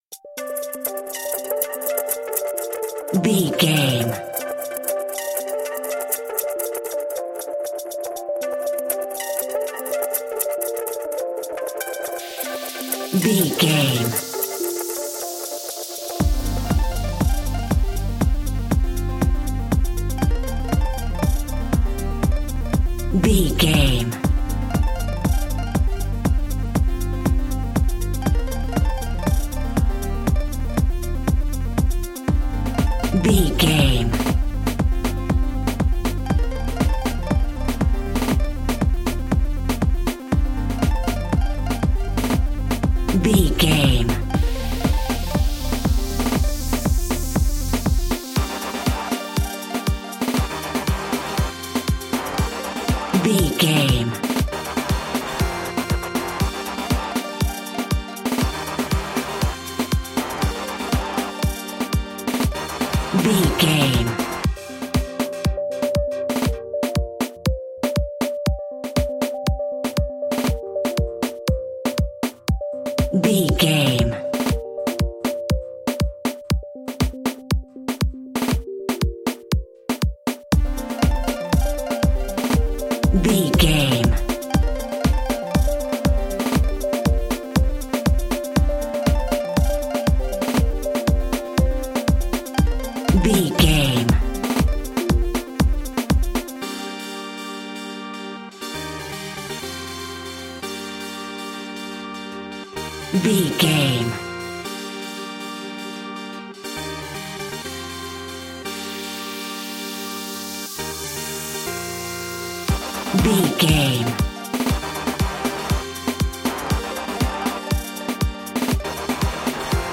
Classic reggae music with that skank bounce reggae feeling.
In-crescendo
Aeolian/Minor
dub
laid back
chilled
off beat
drums
skank guitar
hammond organ
percussion
horns